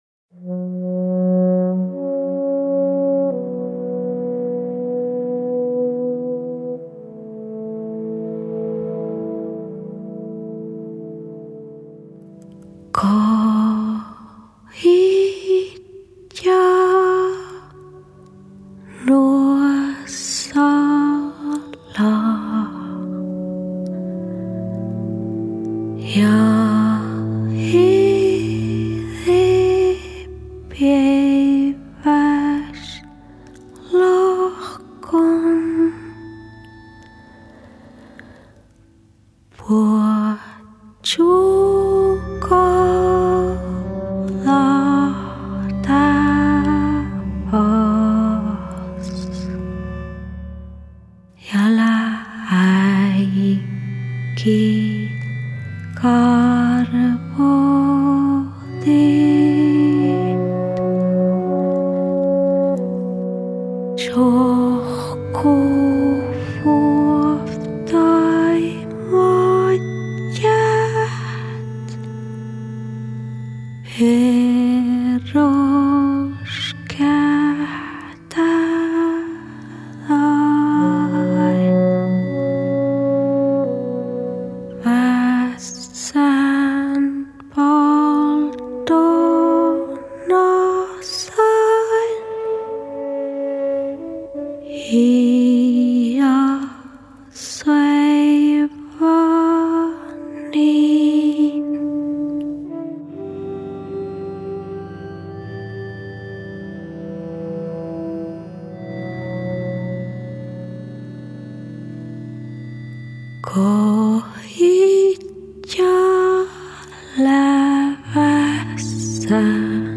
A voz telúrica dos sami